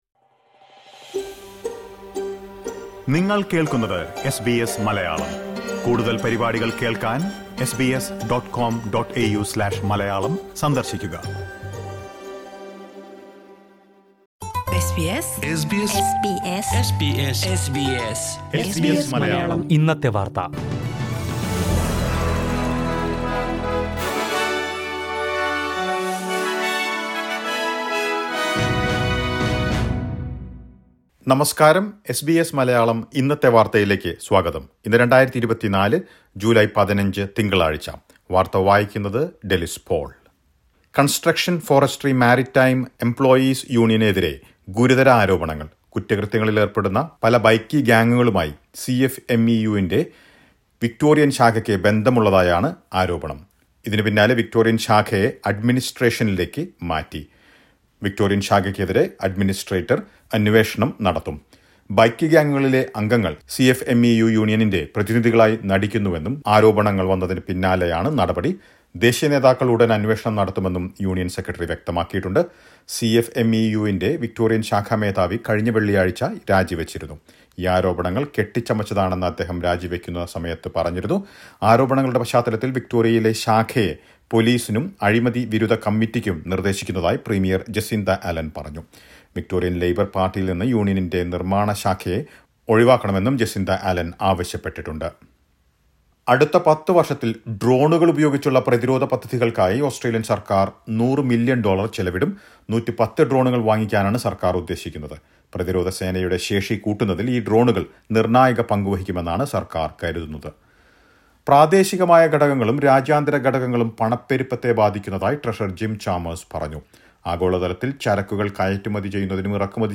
2024 ജൂലൈ 15ലെ ഓസ്‌ട്രേലിയയിലെ ഏറ്റവും പ്രധാന വാര്‍ത്തകള്‍ കേള്‍ക്കാം.